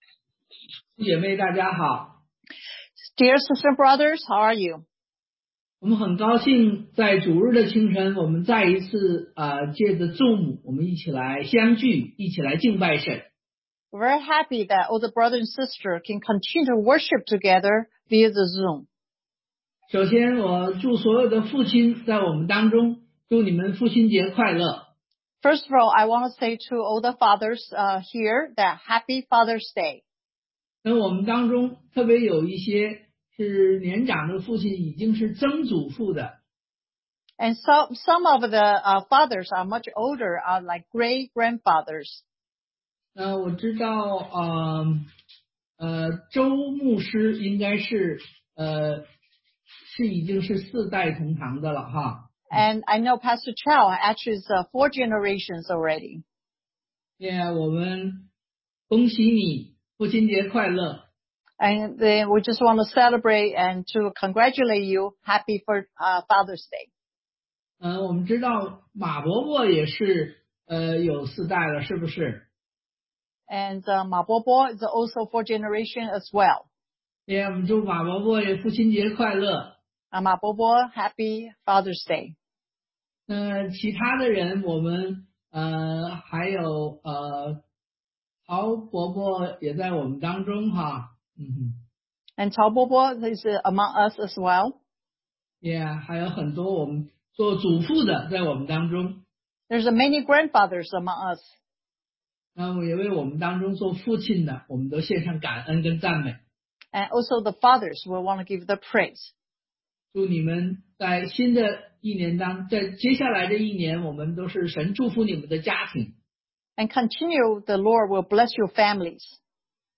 Service Type: Sunday AM
Testimonies 見證